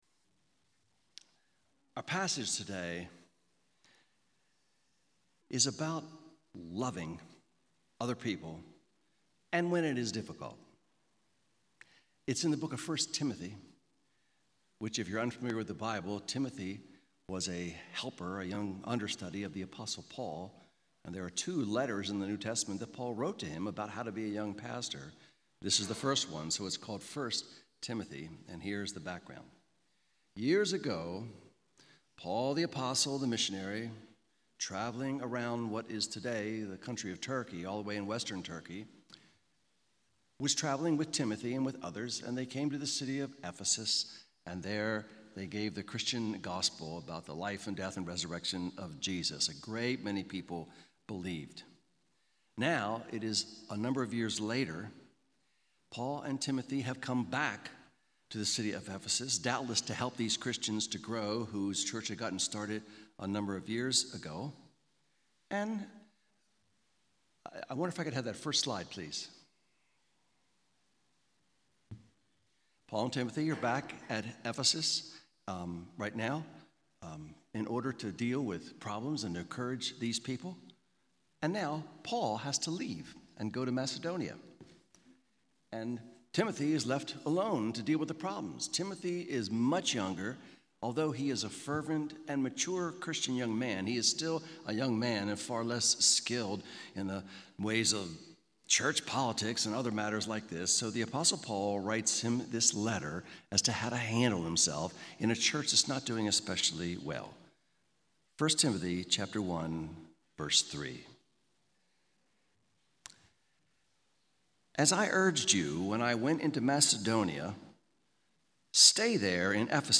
Sermons on 1 Timothy 1:5 — Audio Sermons — Brick Lane Community Church